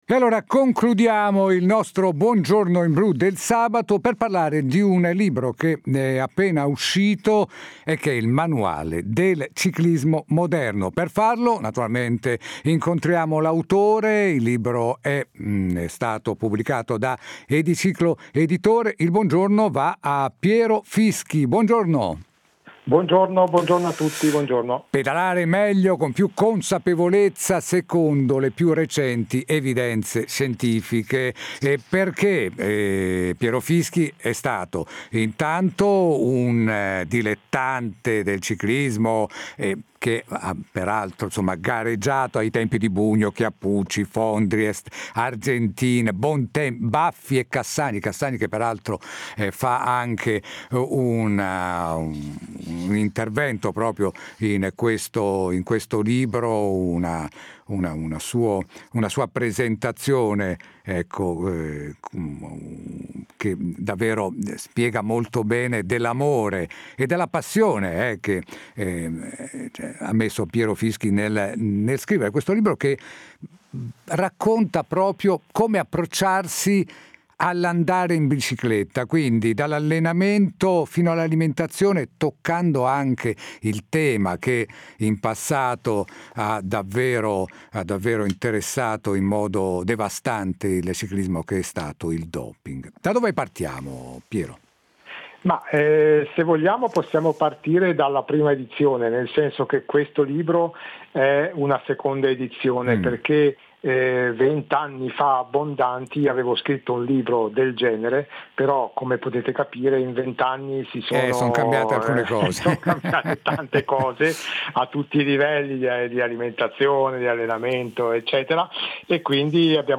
Intervista a Radio Inblu2000 (Uscita libro Manuale del Ciclismo Moderno)